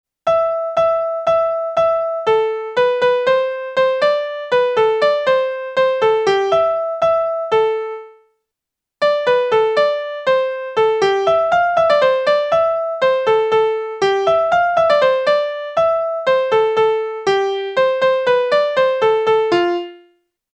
The pitches of some notes are ambiguous.